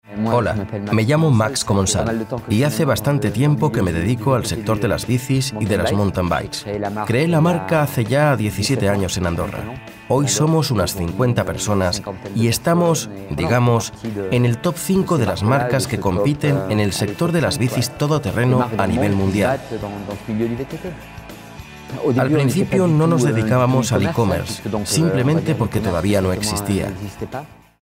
European Spanish Voice over.
kastilisch
Sprechprobe: eLearning (Muttersprache):
My voice is warm, smooth, friendly, authoritative, clear, enthusiastic and most importantly, believable. I can be warm and deep but also energetic and fresh.